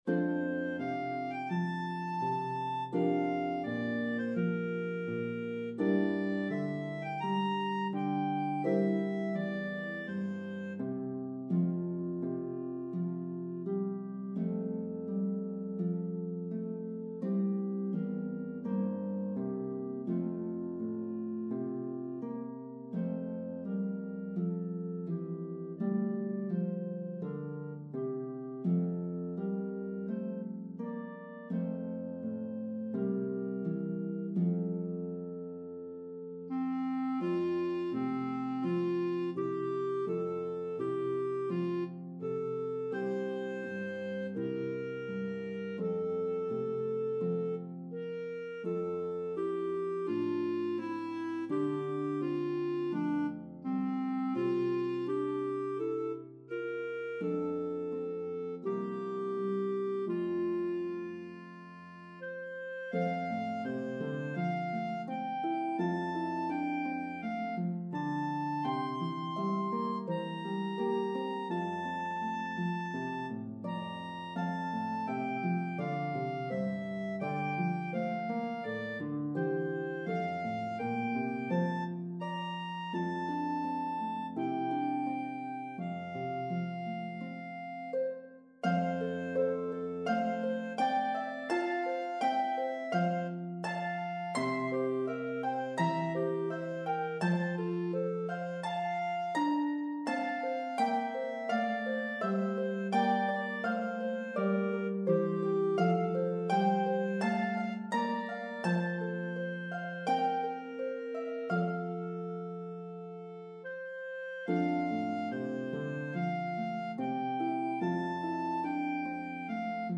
Harp and Clarinet version